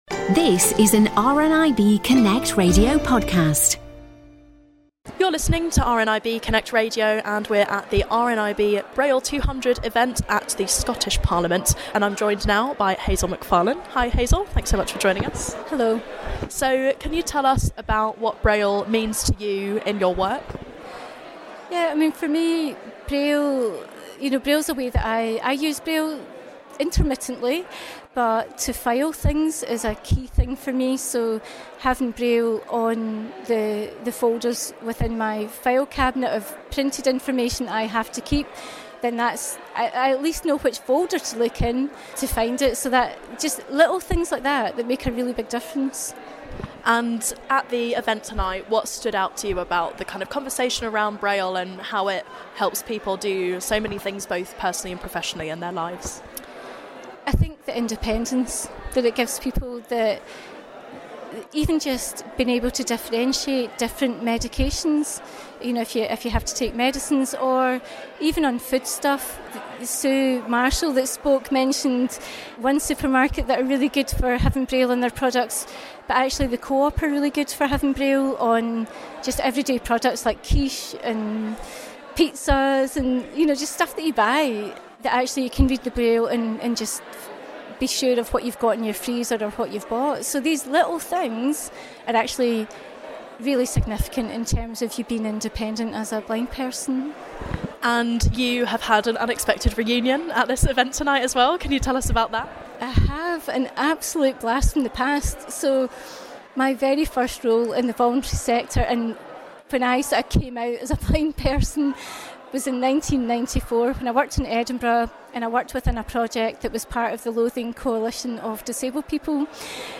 RNIB Scotland recently organised a reception at the Scottish Parliament to celebrate 200 years of braille.